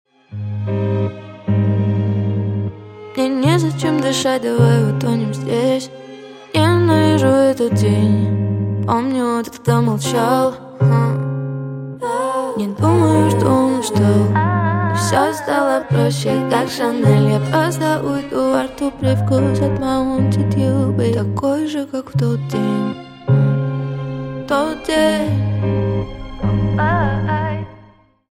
Спокойные И Тихие Рингтоны » # R&B Soul Рингтоны